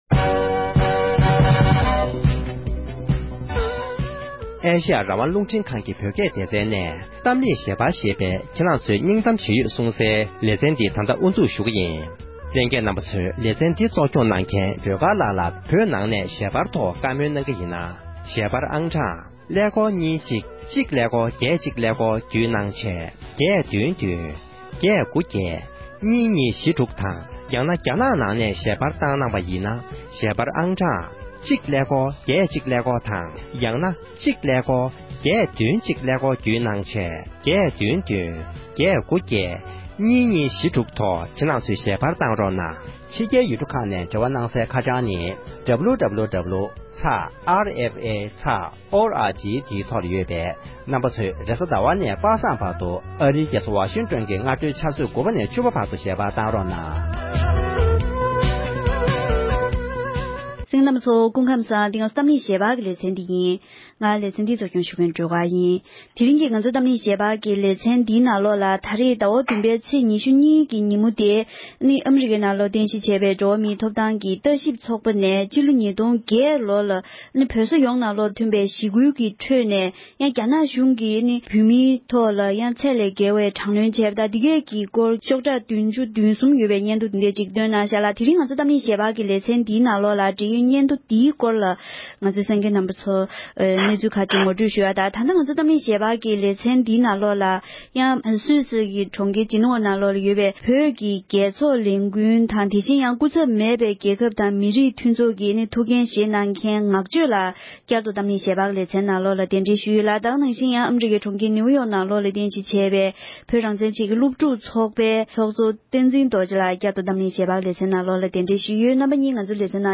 དེ་རིང་གི་གཏམ་གླེང་ཞལ་པར་གྱི་ལེ་ཚན་ནང་དུ